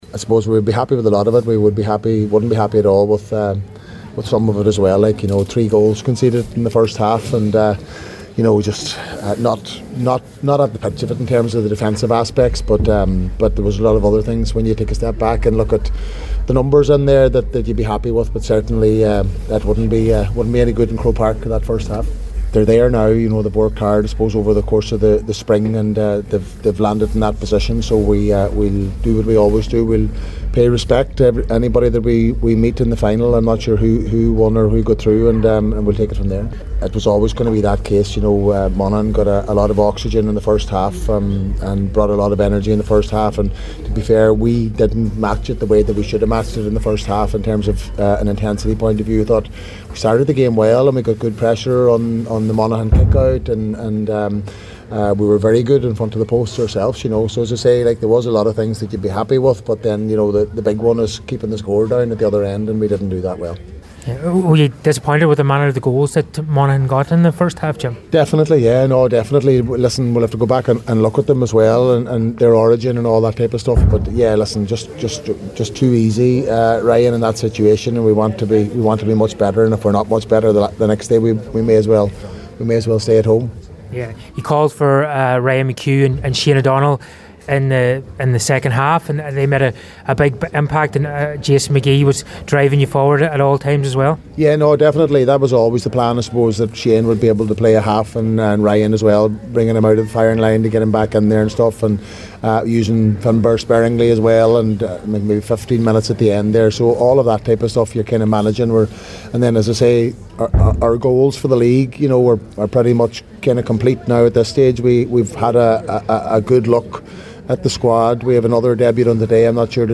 Donegal Manager Jim McGuinness acknowledged plenty of positives and negatives in this afternoon’s hard-fought win over The Farney County when he spoke to the media after the game.